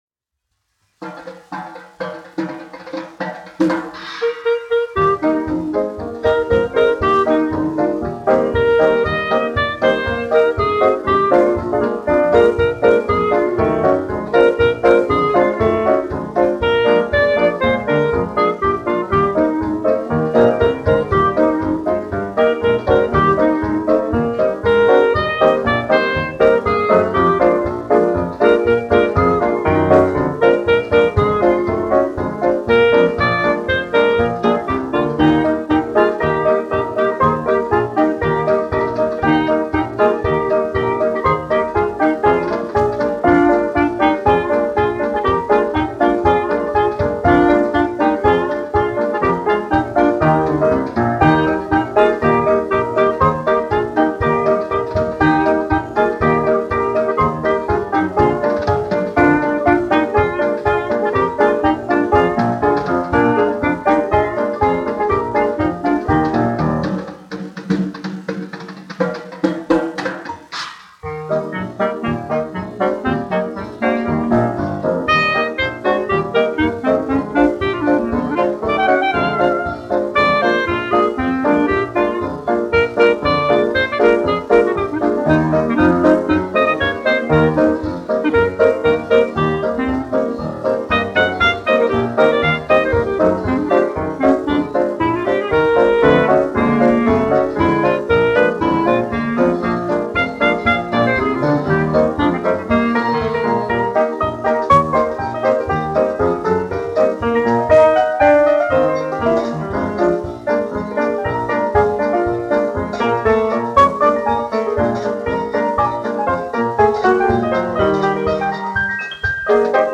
1 skpl. : analogs, 78 apgr/min, mono ; 25 cm
Fokstroti
Populārā instrumentālā mūzika
Skaņuplate